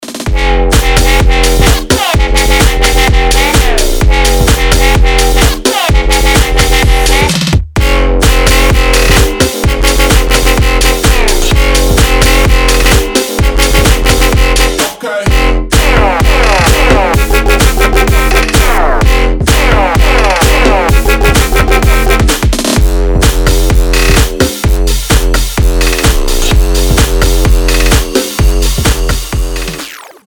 громкие
мощные
dance
Electronic
EDM
мощные басы
Bass House
Мощно топит!